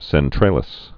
fovea cen·tra·lis
(sĕn-trālĭs)